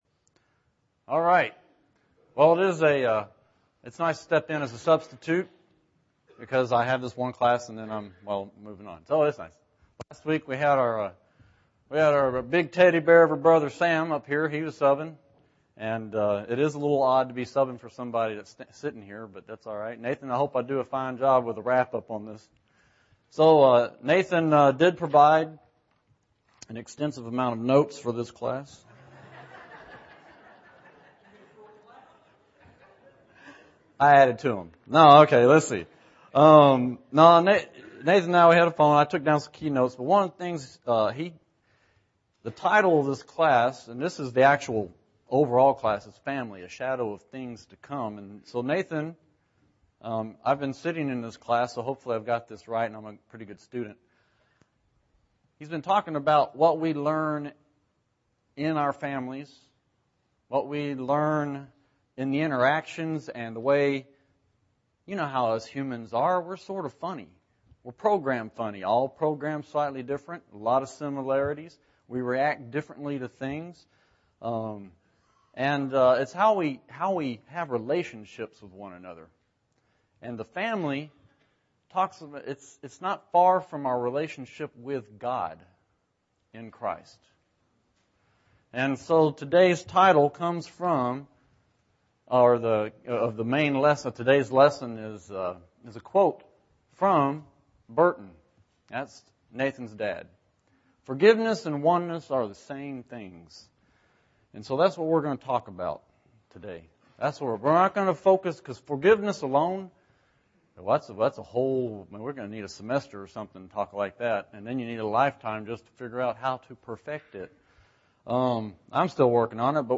Forgiveness and Oneness are the Same Things (12 of 12) – Bible Lesson Recording